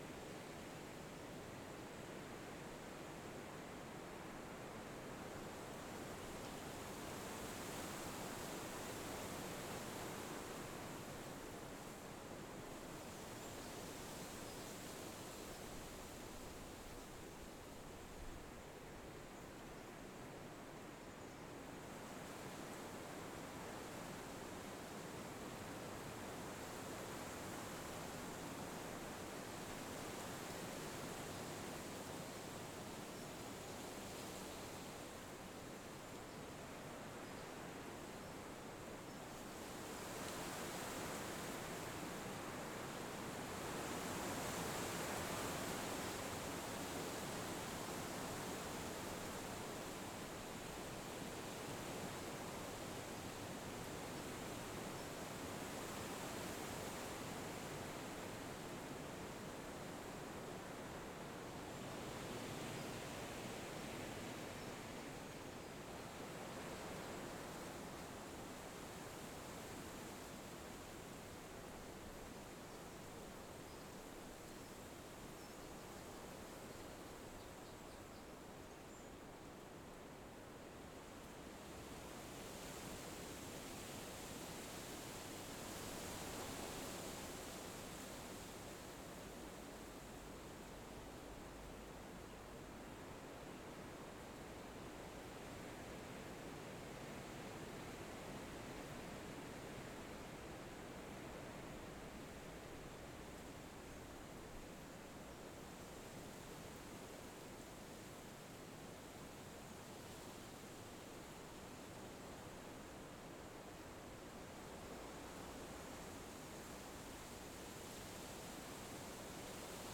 ambienceWind1Seamless.ogg